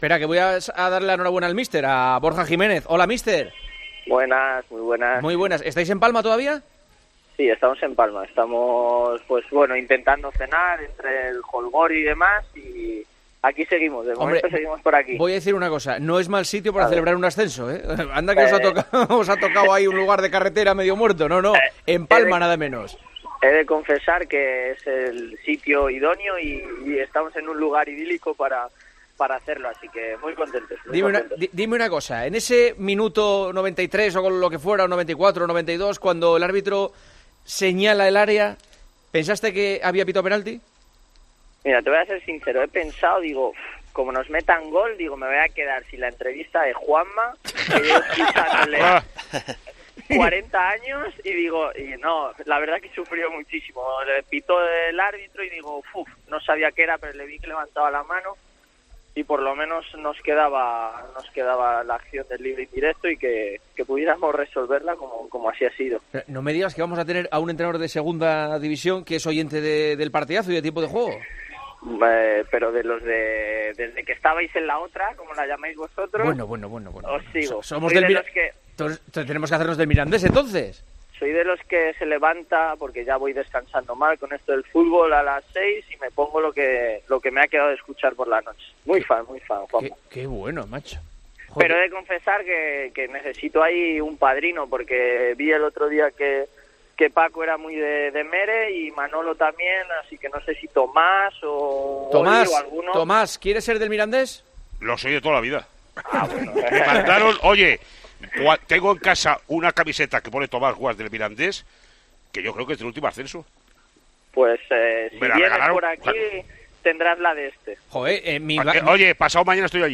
El entrenador del Mirandés habló en Tiempo de Juego y se mostró feliz tras el sufrido ascenso a segunda de su equipo en Palma ante el Atlético Baleares.